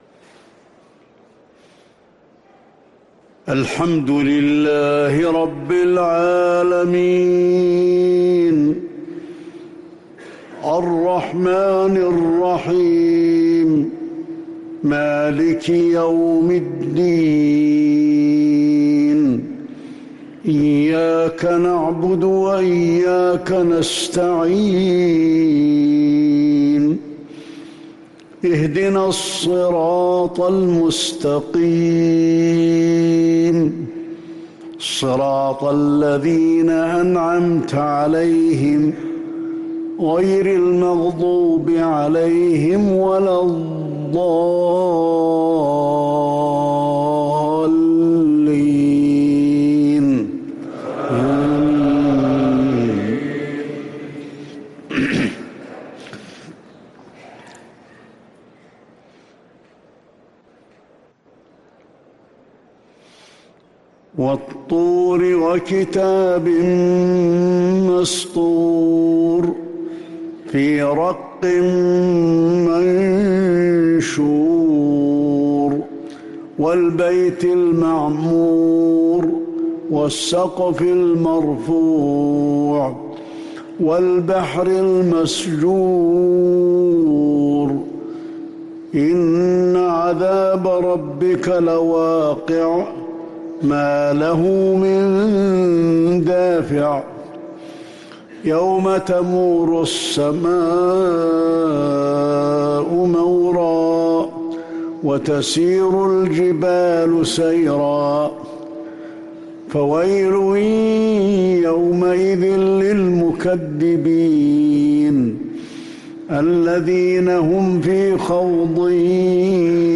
فجر الخميس 6 محرم 1444هـ سورة الطور كاملة |  Fajr prayer from Surah AtTur 4-8-2022 > 1444 🕌 > الفروض - تلاوات الحرمين